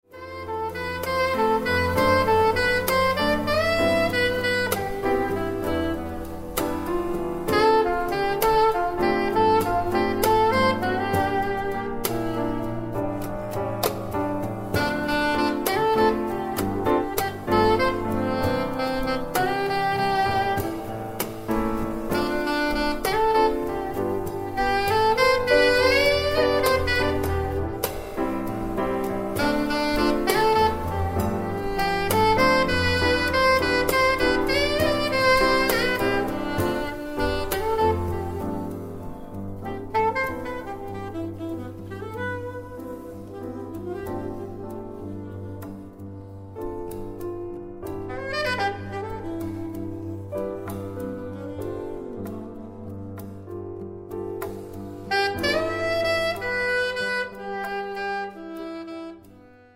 sax soprano e alto, voce
pianoforte
batteria, percussioni, cajon, tabla